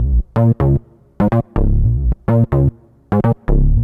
cch_bass_loop_grooved_125_Db.wav